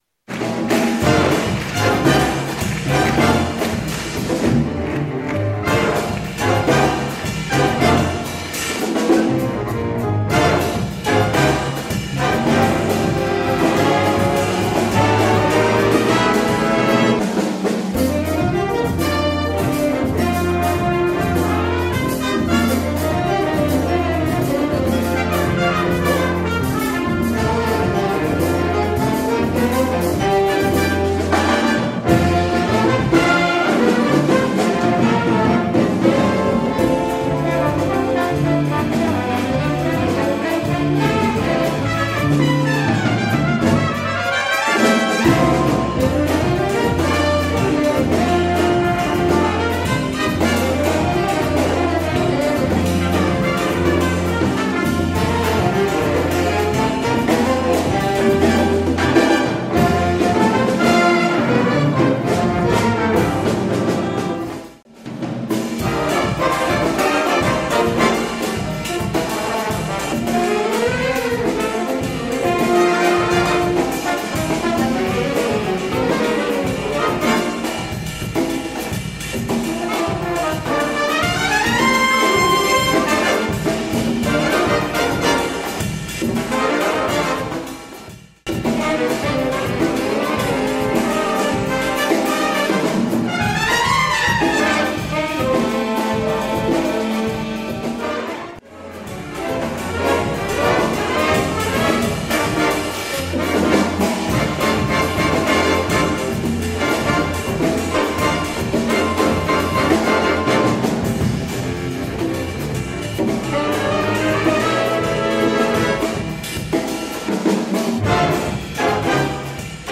Category: big band
Style: Latin funk
Solos: open
Instrumentation: big band (4-4-5, rhythm (4)